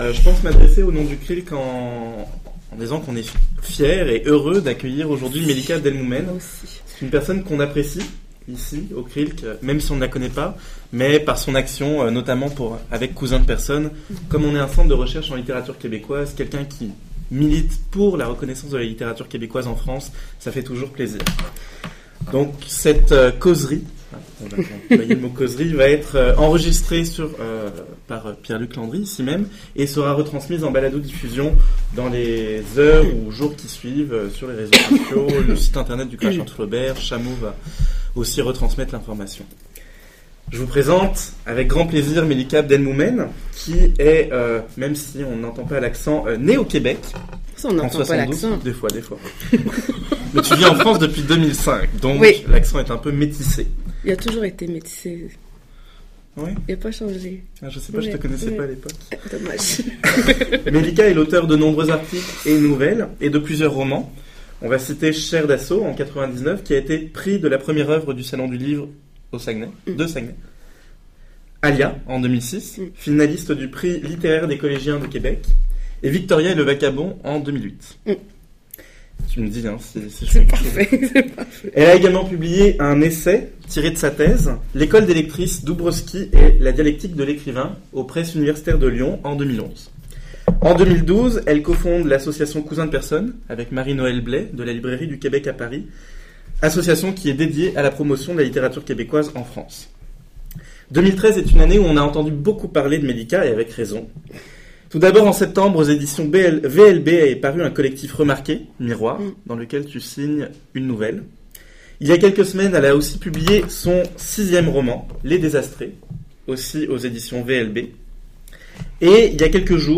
Le Crachoir de Flaubert remercie le CRILCQ d’avoir permis l’enregistrement et la diffusion de cette causerie.